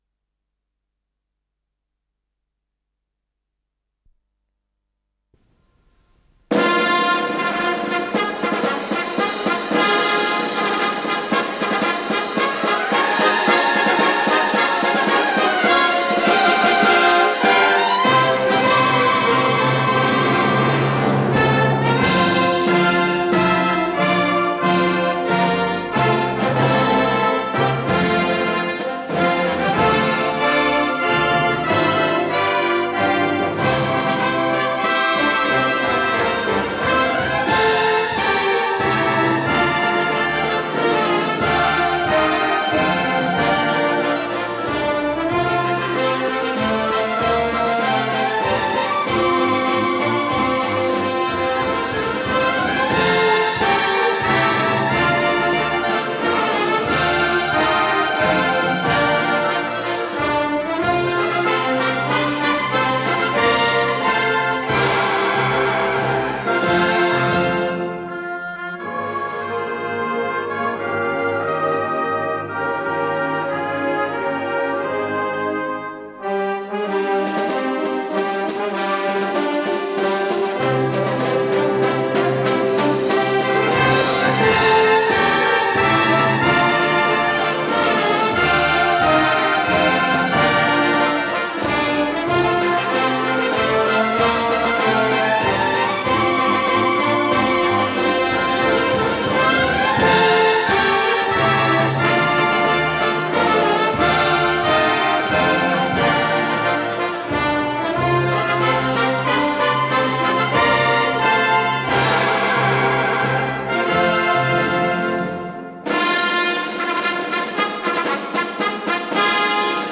с профессиональной магнитной ленты
ВариантДубль моно